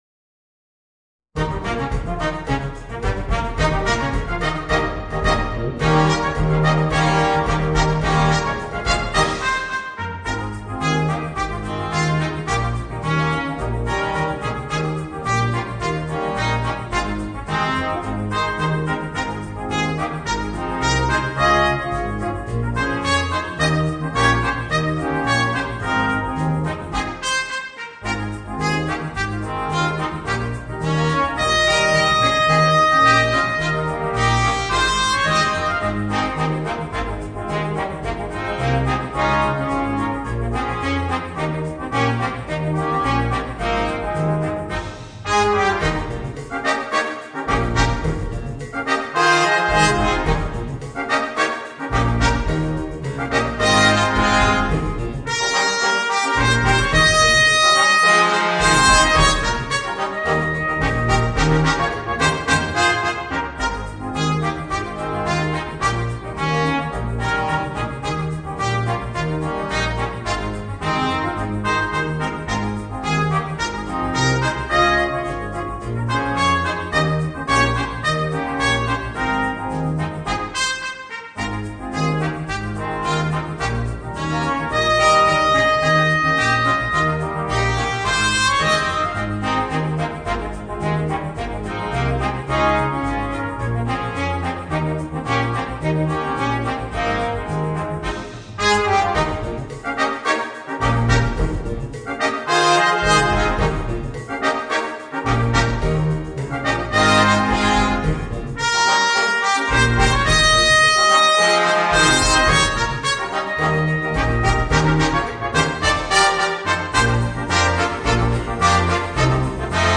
Voicing: 2 Trumpets, Trombone, Euphonium and Tuba